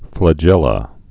(flə-jĕlə)